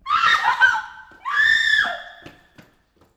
girlScreamNo.wav